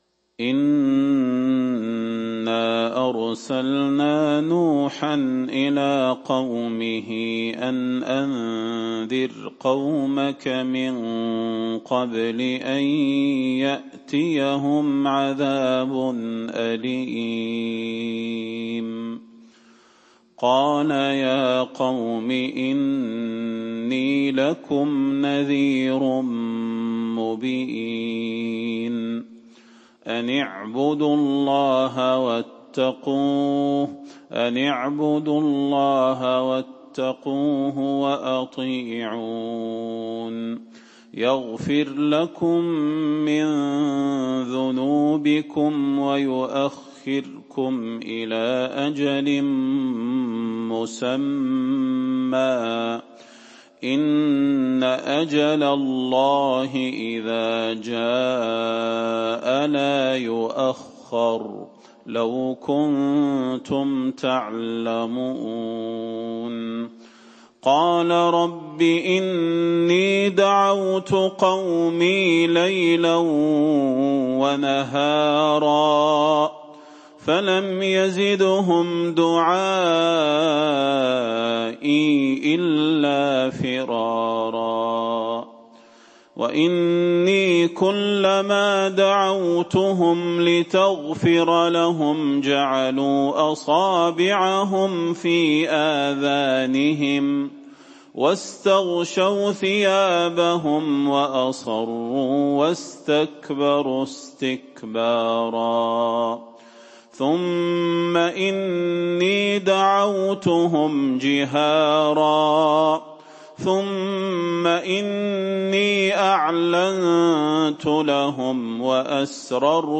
سورة نوح | ربيع الآخر 1439هـ > السور المكتملة للشيخ صلاح البدير من الحرم النبوي 🕌 > السور المكتملة 🕌 > المزيد - تلاوات الحرمين